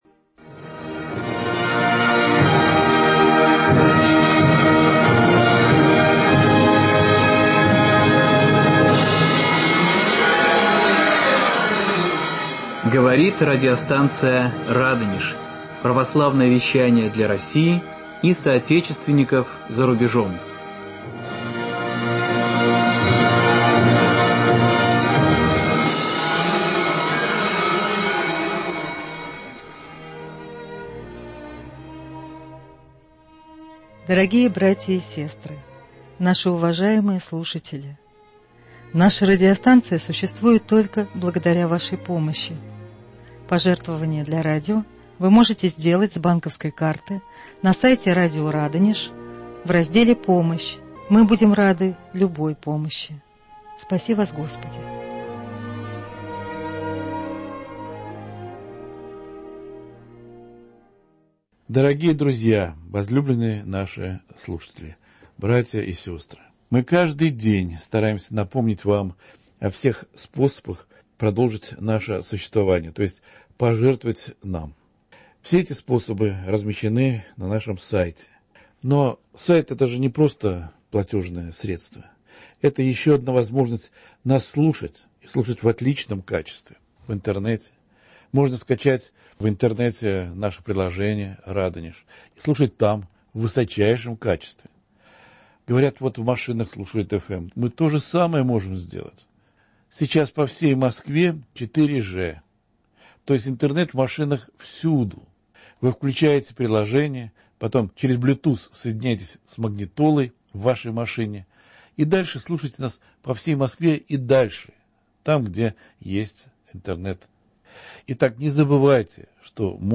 Беседа с преосвященным епископом Гедеоном (Харон).